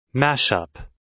Mashup.wav